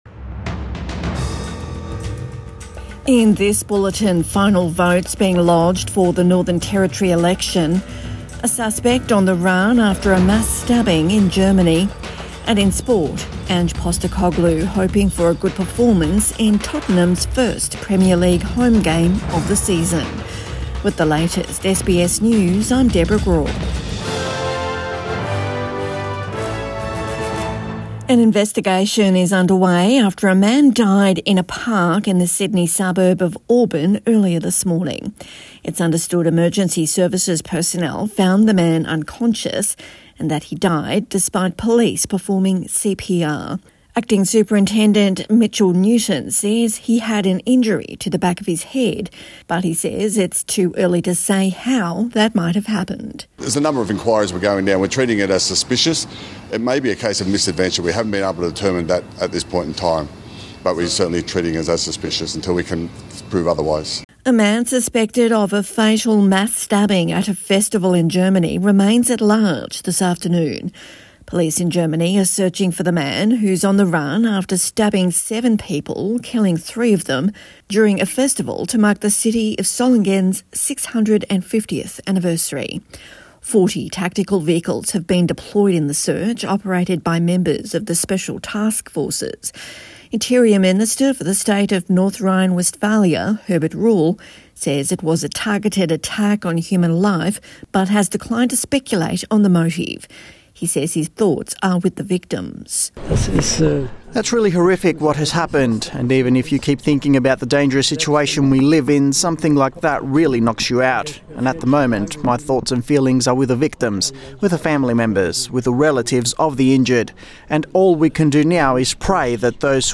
Evening News Bulletin 24 August 2024